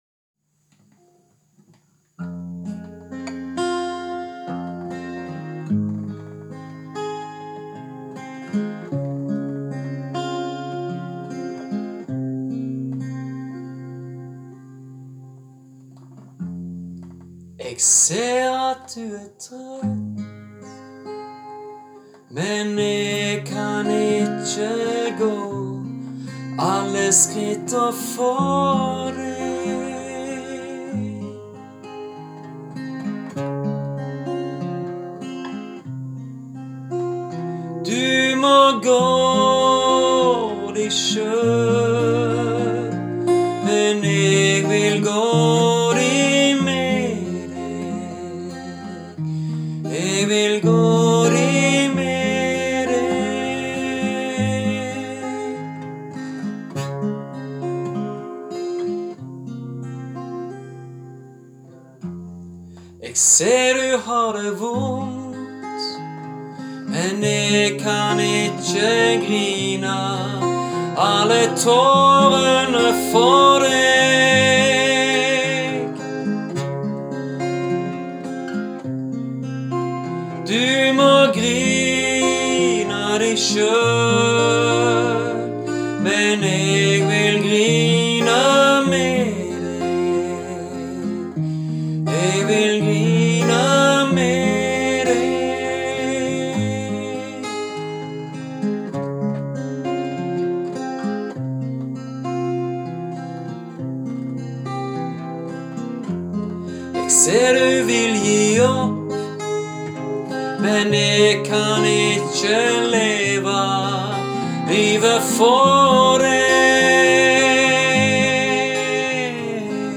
Jeg spiller gitar og piano.